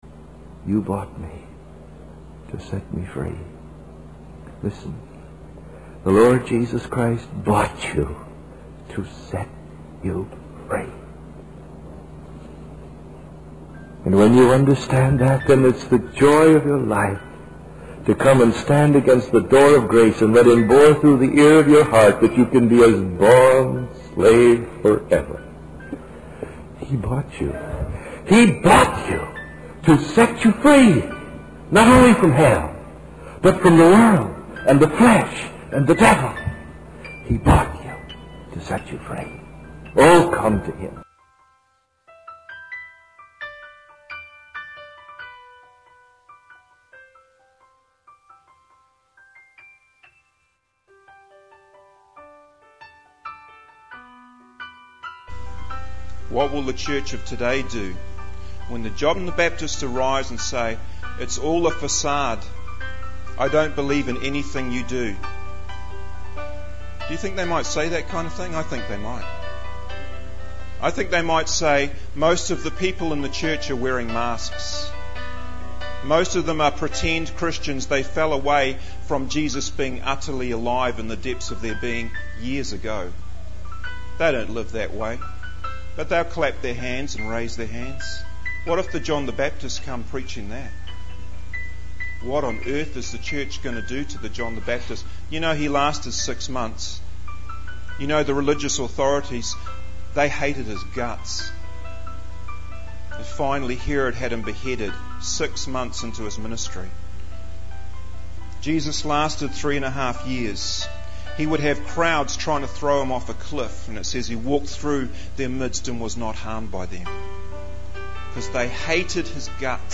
In this sermon, the speaker emphasizes the importance of examining oneself to ensure that they are truly in the faith. He highlights the assurance of salvation as a key focus of preaching in revival.